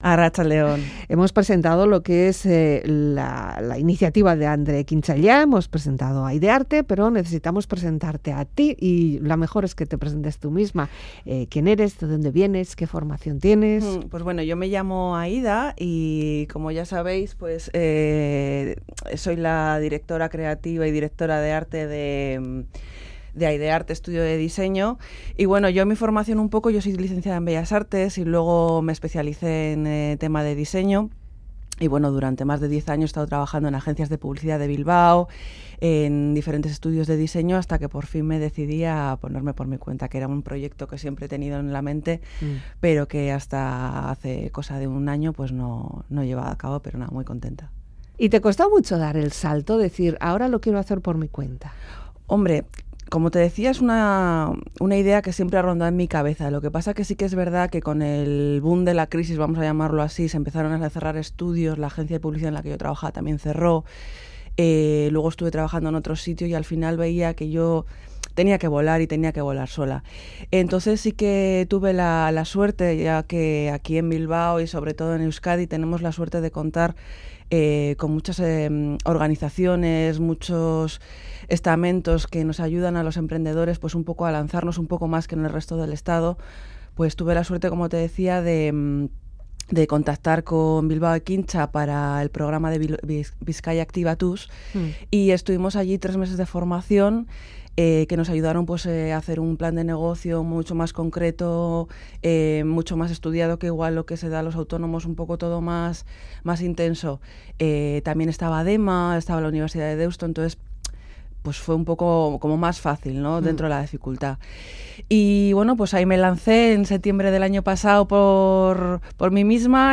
charla con nosotros sobre cómo puso en marcha su empresa, cómo trabaja y con qué sueña.